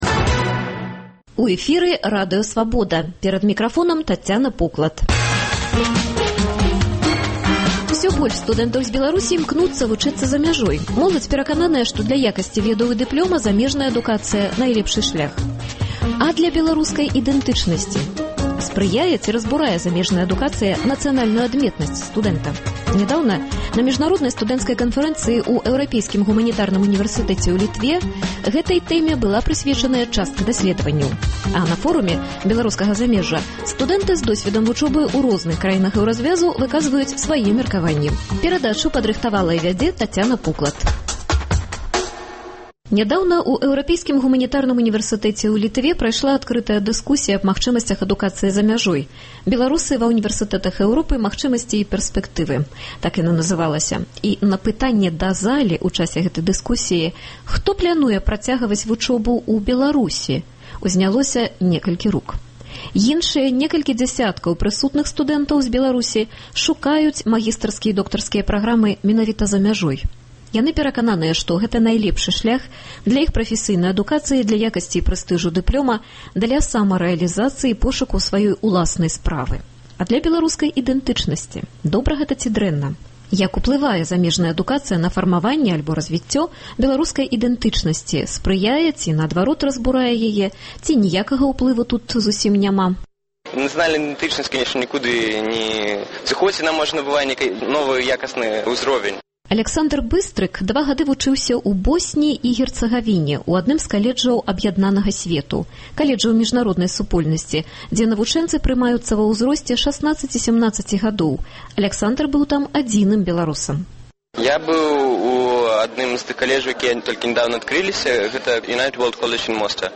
Як уплывае замежная адукацыя на фармаваньне і разьвіцьцё беларускай ідэнтычнасьці – тэму абмяркоўваюць студэнты з розных краін Эўразьвязу.